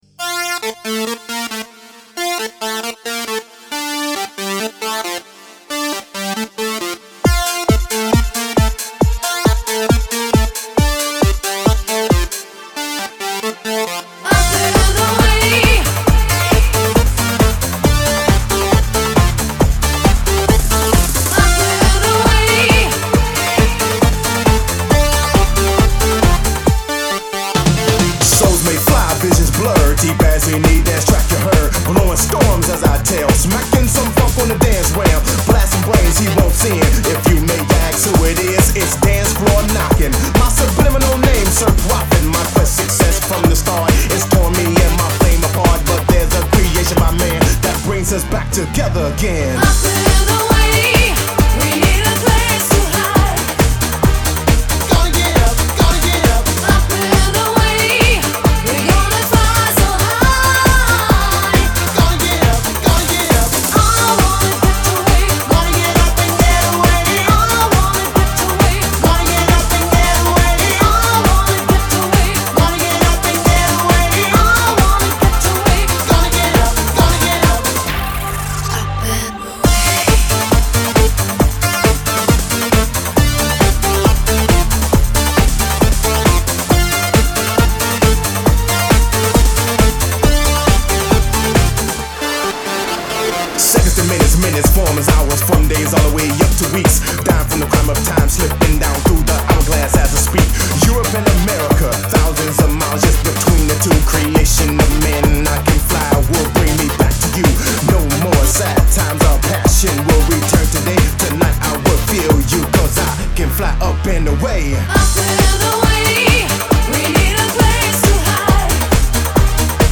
Ретро музыка 90-х